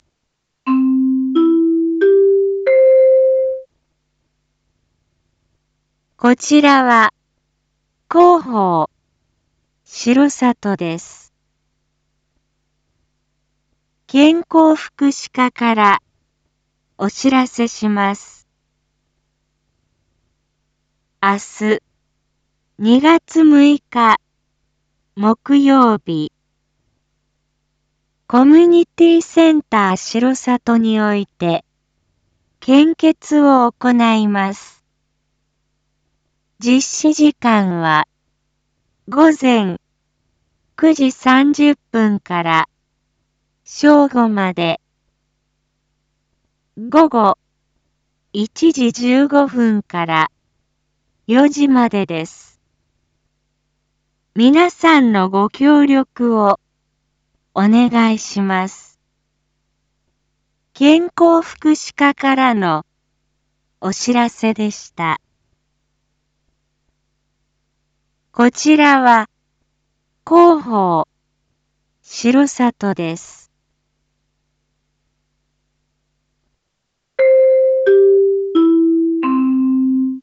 一般放送情報
Back Home 一般放送情報 音声放送 再生 一般放送情報 登録日時：2025-02-05 19:01:23 タイトル：献血ご協力のお願い インフォメーション：こちらは、広報しろさとです。